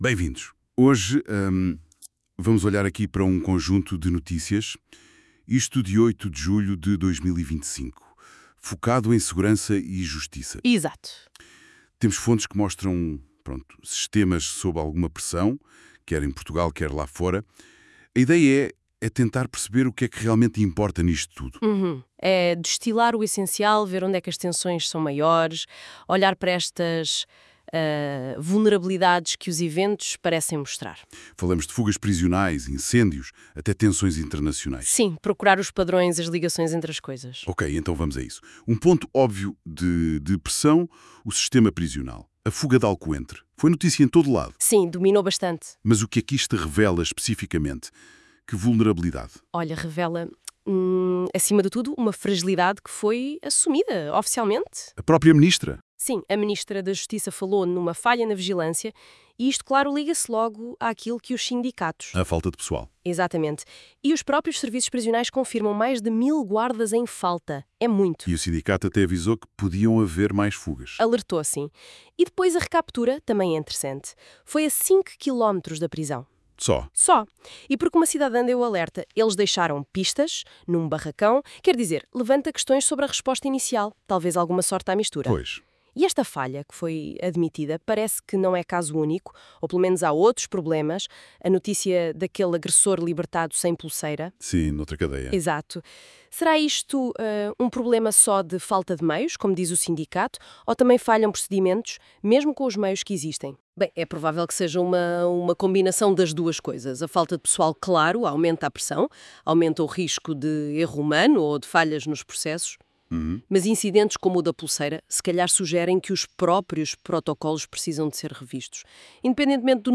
Síntese áudio: